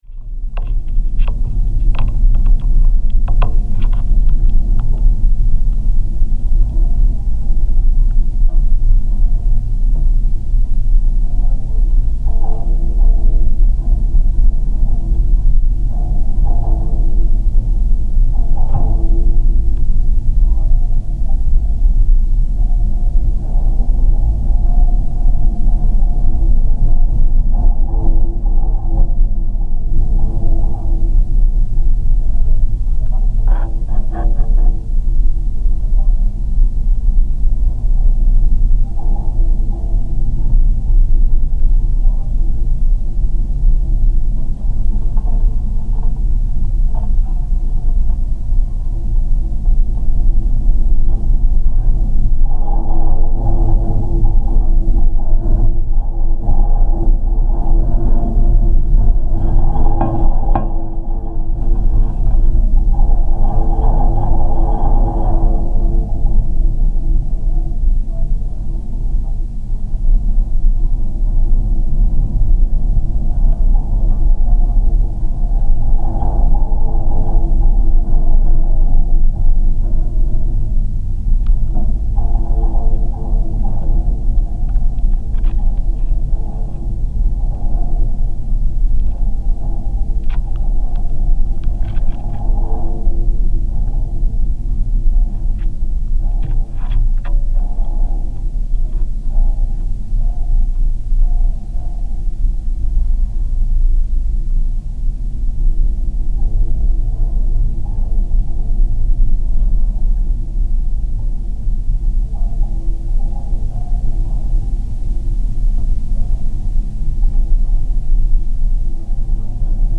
Inside an electricity pylon
This is a recording made with a seismic microphone attached to an electricity pylon in the English countryside in Wytham Woods, Oxfordshire.
The sounds come from the wind vibrating the entire structure, as captured by this specialist microphone.